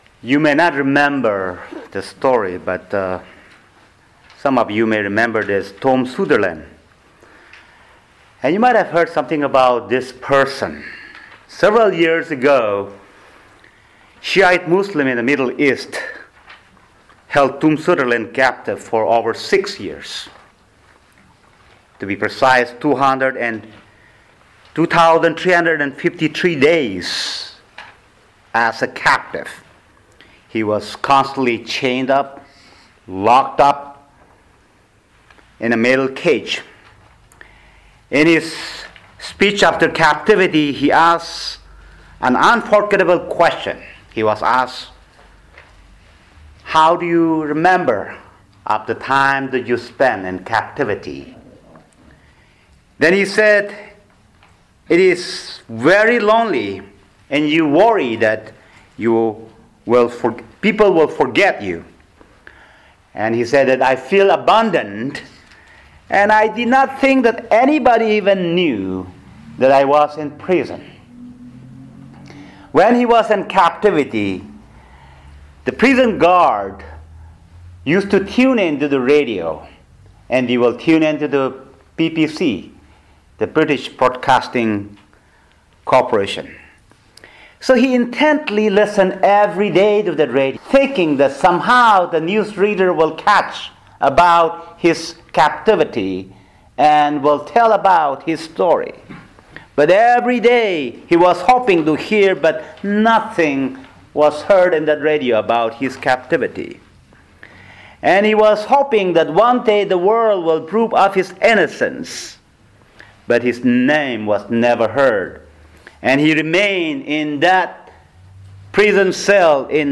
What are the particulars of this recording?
Sunday Worship Service December 29